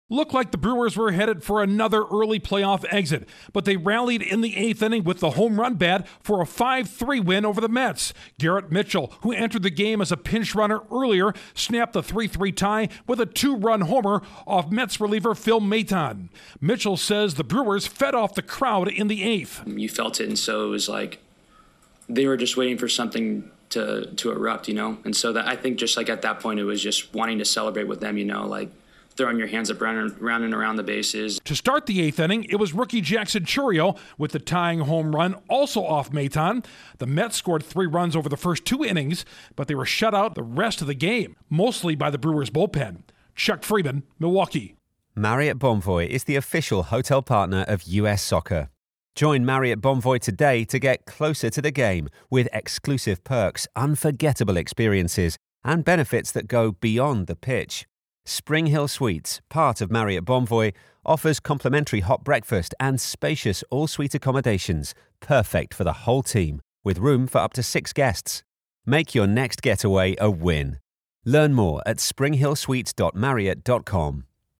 The Brewers send their Wild Card Series to a deciding third game. Correspondent